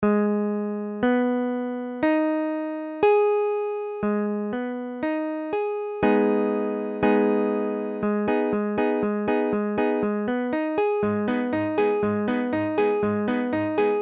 Tablature Abm.abcAbm : accord de La bémol mineur
Mesure : 4/4
Tempo : 1/4=60
A la guitare, on réalise souvent les accords en plaçant la tierce à l'octave.
La bémol mineur Barré IV (la bémol case 4 mi bémol case 6 doigt 3 la bémol case 6 doigt 4 do bémol case 4 mi bémol case 4 la bémol case 4)